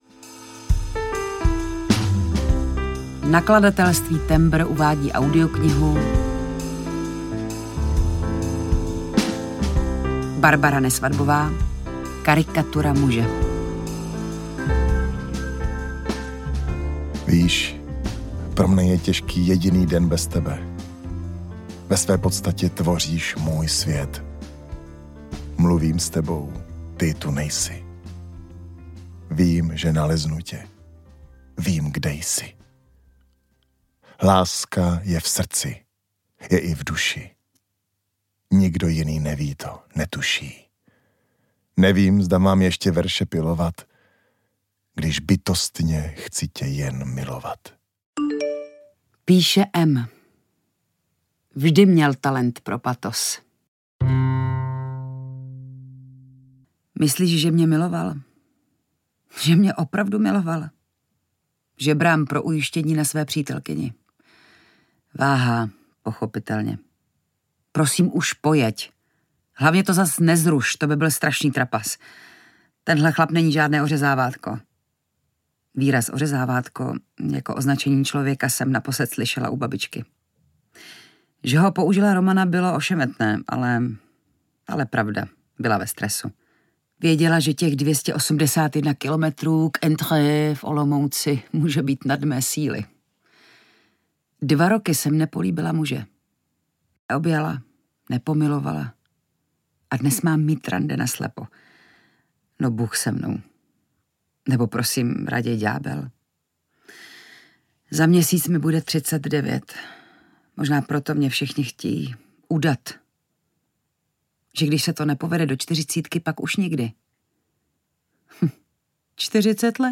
Karikatura muže audiokniha
Ukázka z knihy
• InterpretJitka Čvančarová, Igor Orozovič